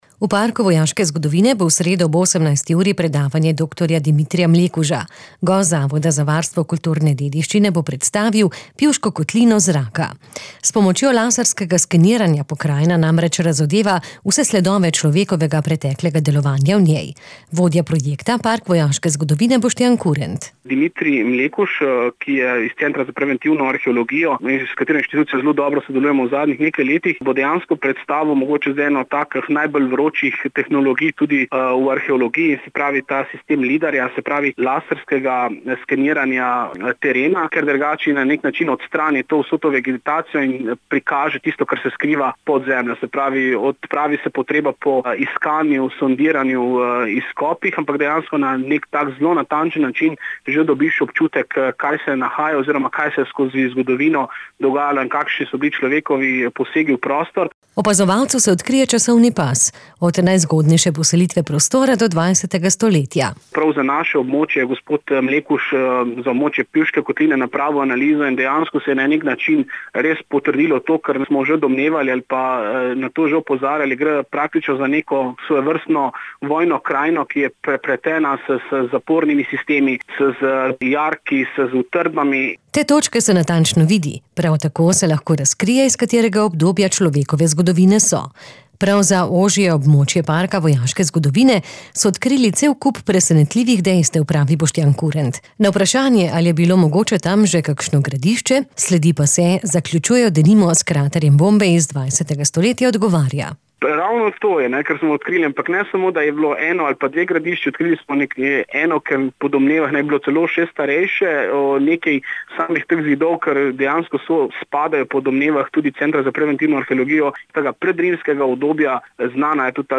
p320-27-predavanje-o-lidarskih-posnetkih-pivskega.mp3